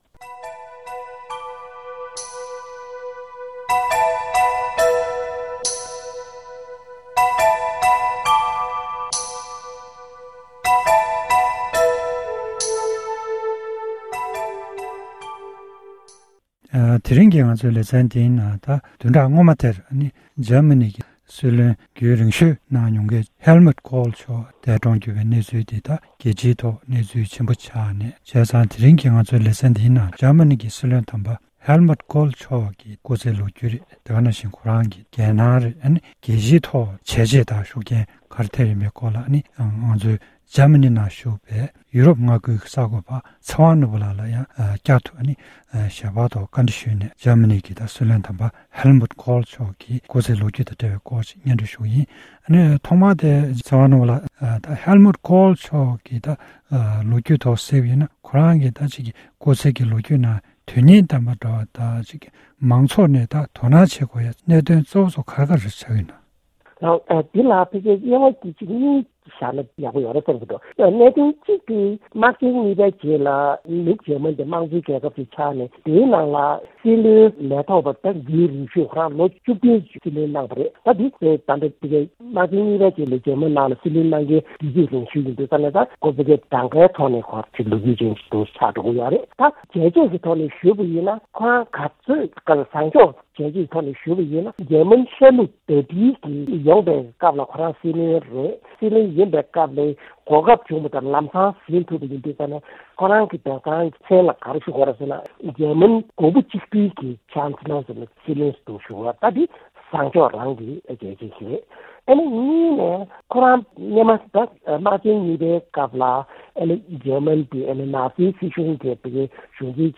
གནས་འདྲི་ཞུས་པ་ཞིག་གཤམ་ལ་གསན་རོགས་གནང་།།